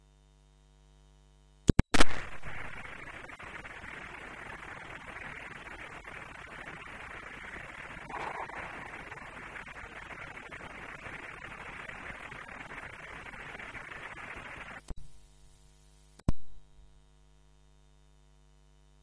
This is a fact and an ultrasound microphone will pick up this trace.
If you listen carefully you will hear it in the long stretch and at the beginning of the trace.
30-aug-electroultrasound-boom.mp3